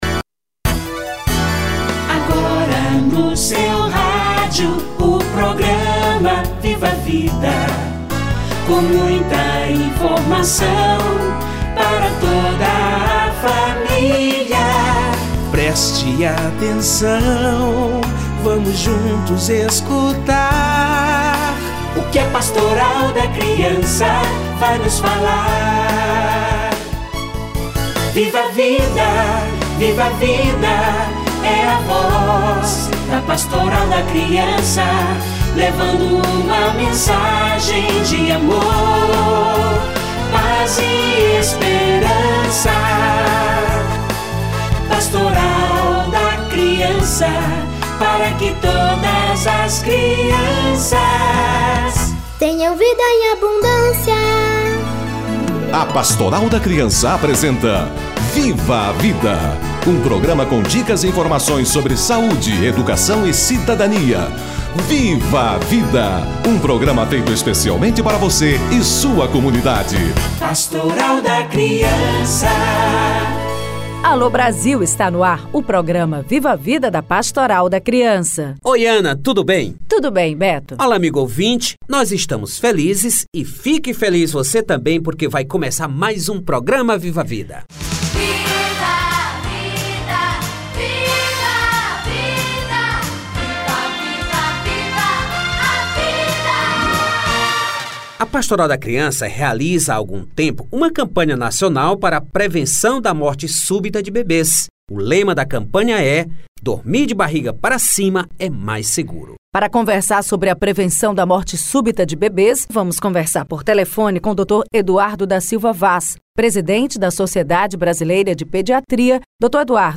Bebê deve dormir de barriga para cima - Entrevista